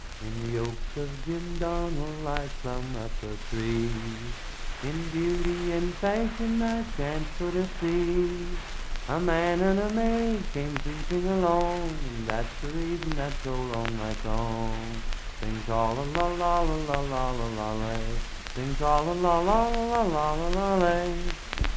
Unaccompanied vocal music
Miscellaneous--Musical
Voice (sung)
Parkersburg (W. Va.), Wood County (W. Va.)